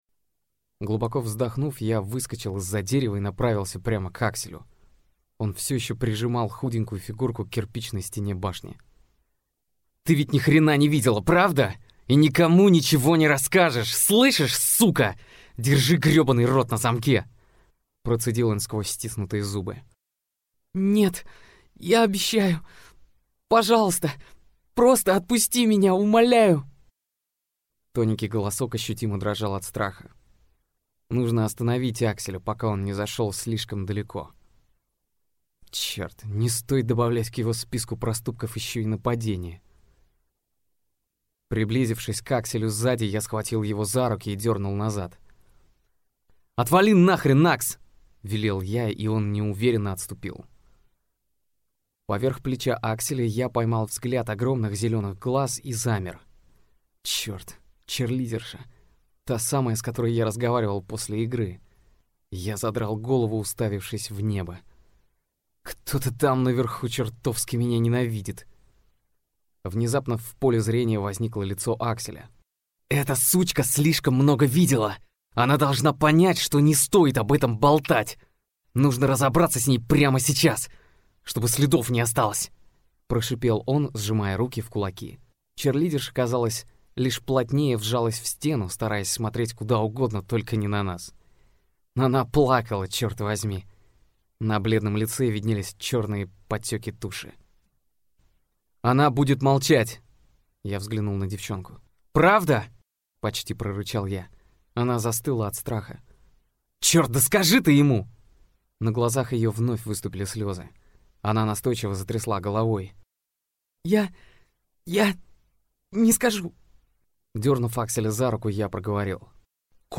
Аудиокнига Братья Карилло. Когда мы упали | Библиотека аудиокниг